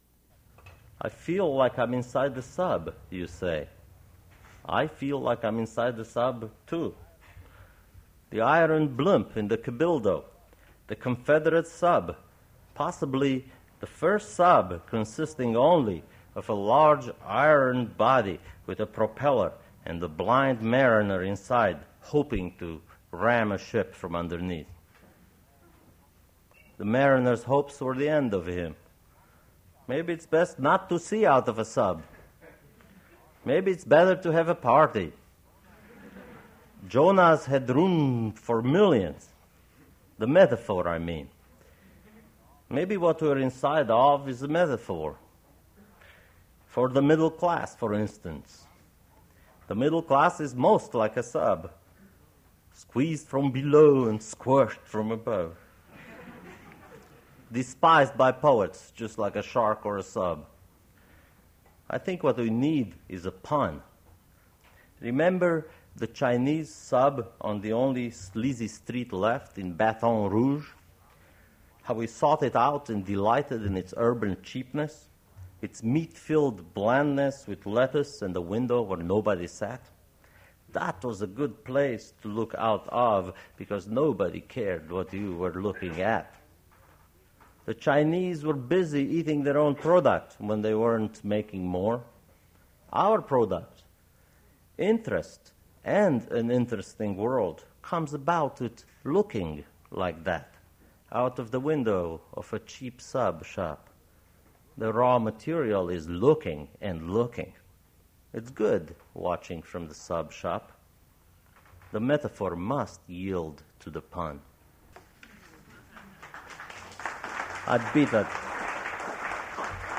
Poetry reading featuring Andrei Codrescu
Attributes Attribute Name Values Description Andrei Codrescu poetry reading at Duff's Restaurant.
Source mp3 edited access file was created from unedited access file which was sourced from preservation WAV file that was generated from original audio cassette.
Note Recording starts mid-reading; rest of the recording is music so I cut it